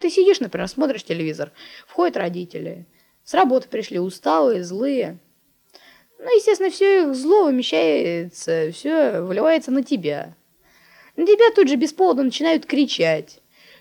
Хороший результат, но слышно "журчание" музыкального шума. Достаточно натуральный тембр (вероятно, еще за счет того, что степень подавления шума ниже, чем в других примерах).